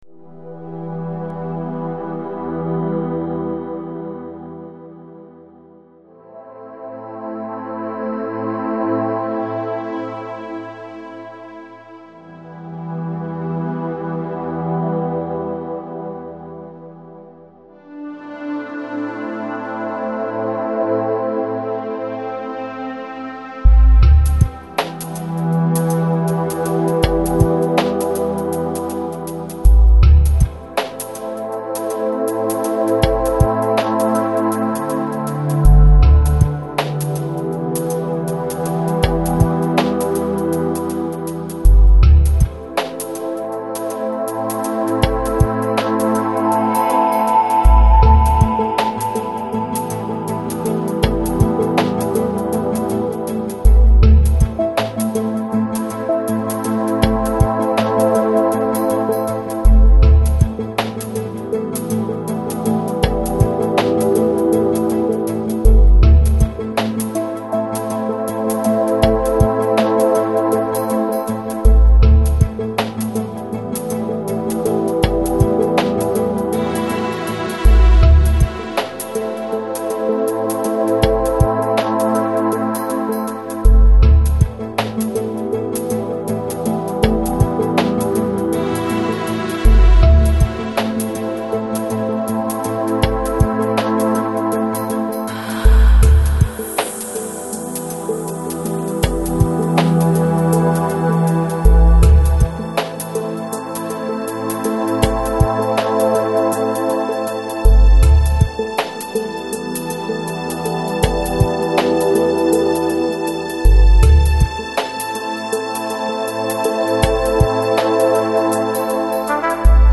Lounge, Chill Out, Ambient Год издания